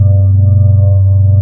AmbDroneK.wav